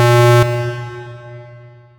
ALARM_Tone_Large_loop_stereo.wav